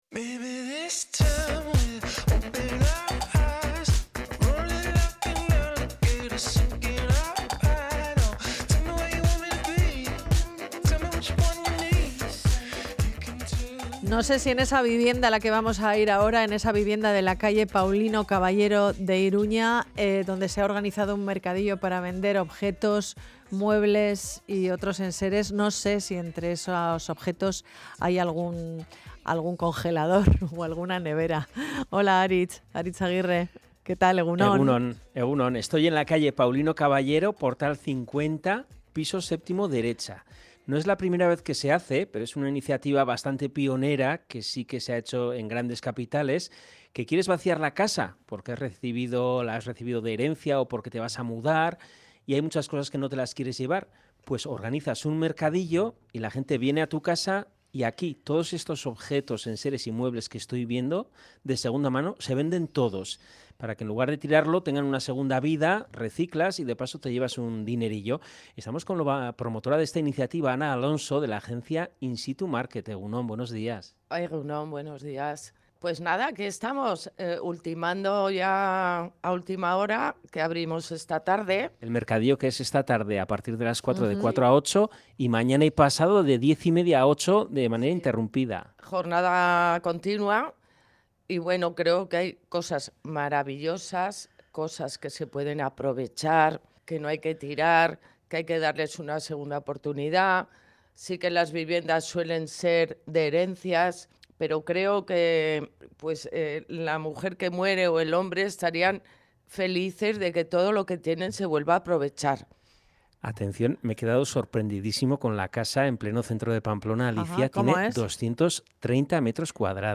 ENTREVISTA_VIVIENDA_MERCADILLO.mp3